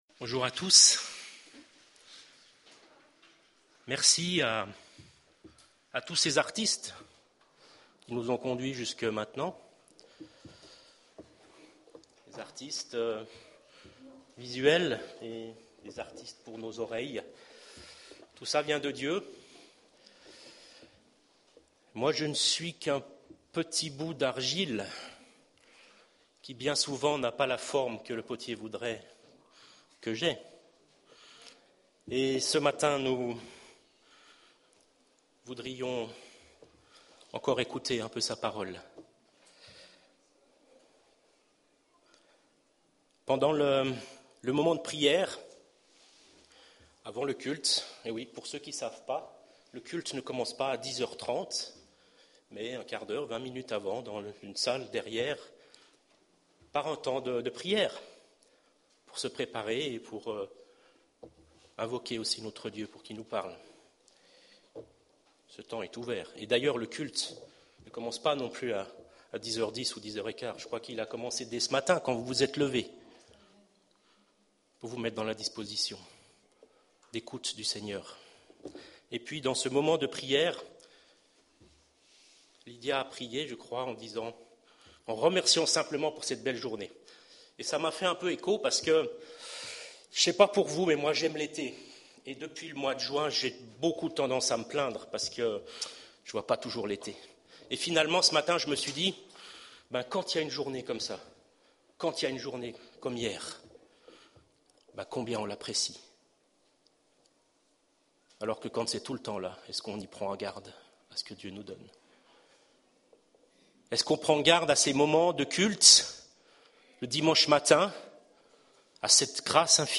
Culte du 14 août